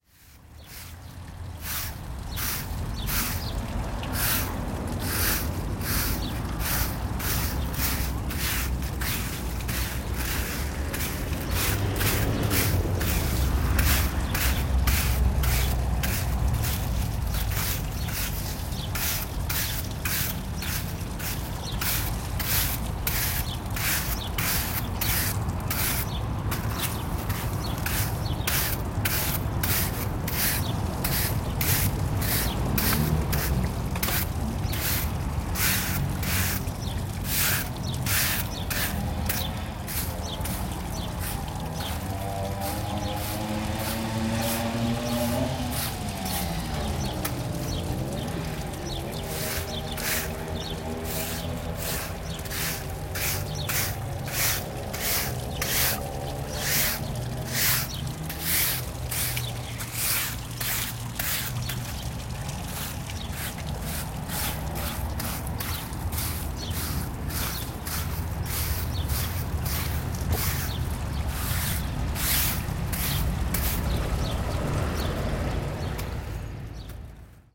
Gravação de um empregado que varre o passeio do jardim. Gravado com Edirol R44 e dois microphones de lapela Audio-Technica AT899.
NODAR.00123 – Viseu: Jardim da Av. Emídio Navarro – Homem a varrer folhas
Tipo de Prática: Paisagem Sonora Rural
Viseu-Jardim-da-Av.-Emídio-Navarro-Homem-a-varrer-folhas.mp3